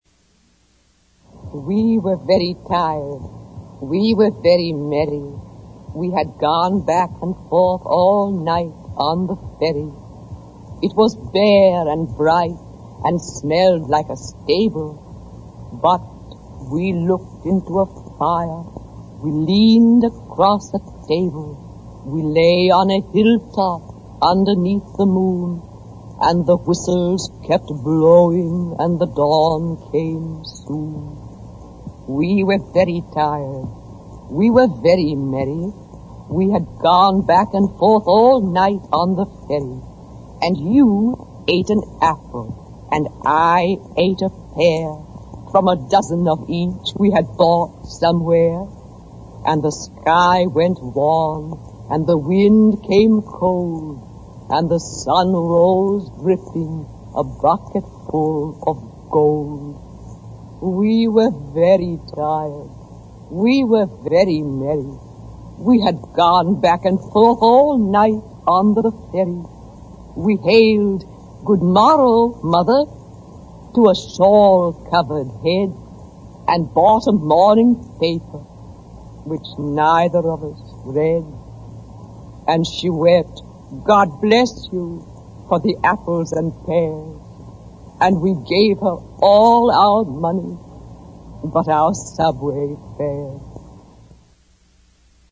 legge Recuerdo
La voce di Edna Saint Vincent Millay cantilenante a tratti, ma ferma e forte, ci arriva dall’inizio del secolo scorso per raccontare di un traghetto notturno, quello di ⇨ Staten Island, che non costa nulla e non si ferma mai, e vaga per tutta la notte, portando due amici, forse innamorati, in un viaggio che finisce regalando delle pere e delle mele, comprate magari come cena per risparmiare, a una donna, una mamma archetipo, con uno scialletto in testa.